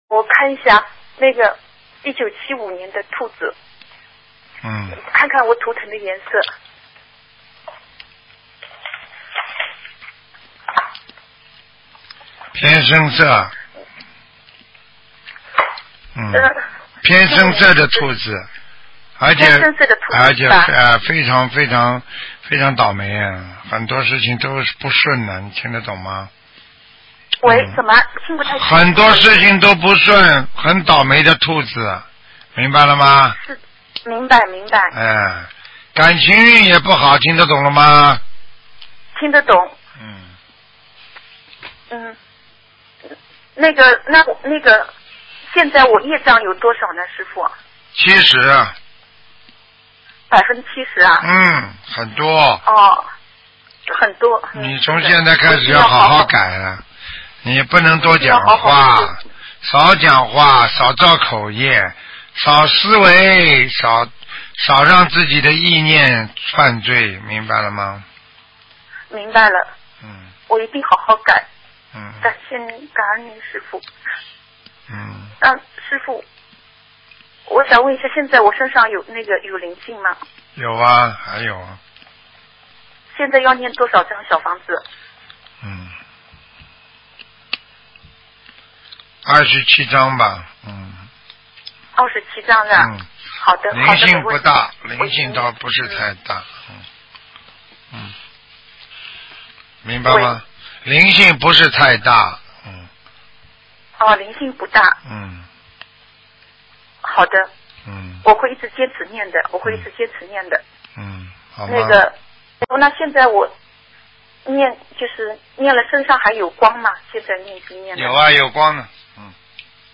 目录：2014年_剪辑电台节目录音集锦